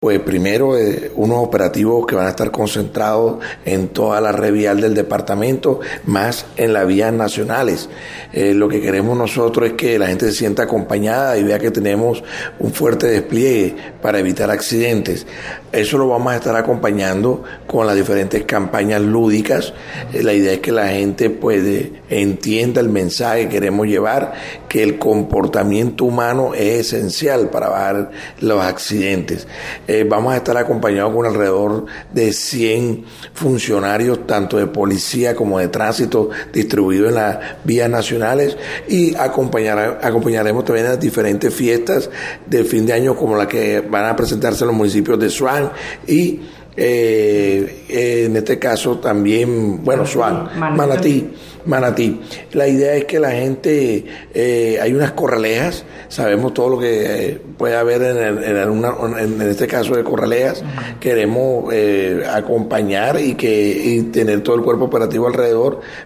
VOZ-CARLOS-GRANADOS-TRANSITO-FIN-AÑO.mp3